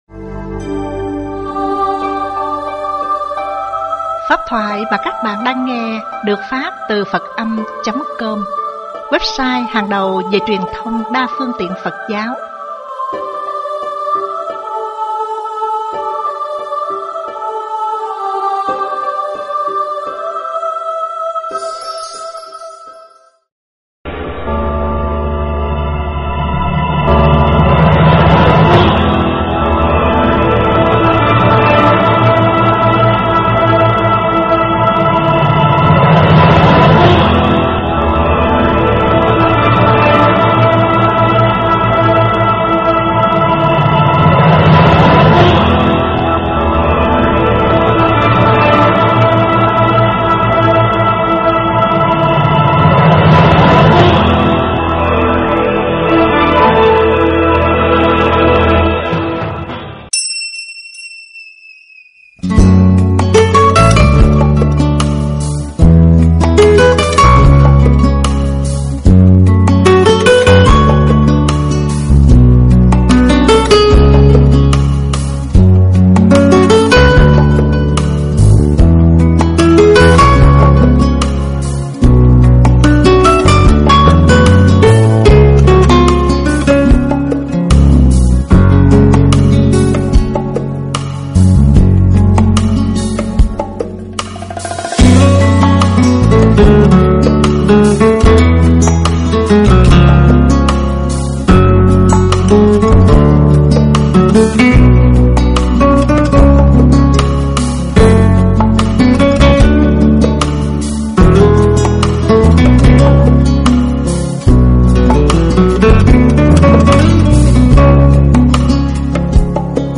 thuyêt giảng ngày 05.10.2013...